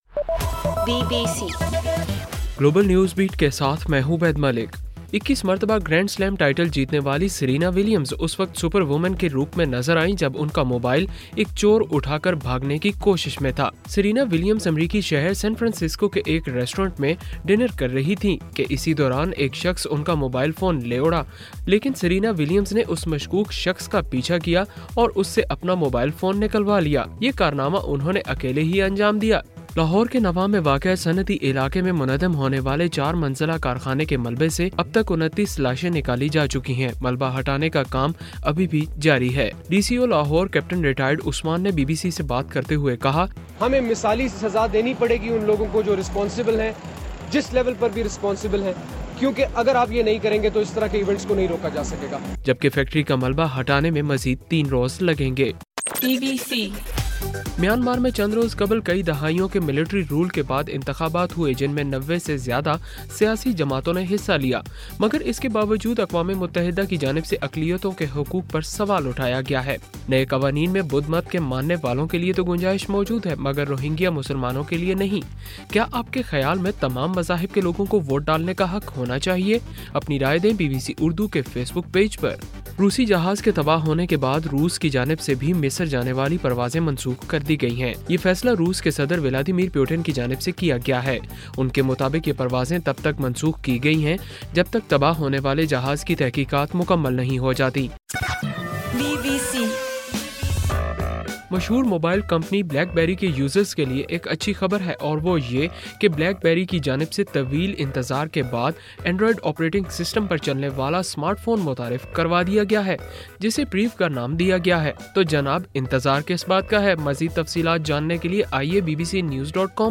نومبر 7: صبح 1 بجے کا گلوبل نیوز بیٹ بُلیٹن